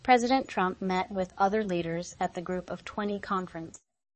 tortoise-tts - (QoL improvements for) a multi-voice TTS system trained with an emphasis on quality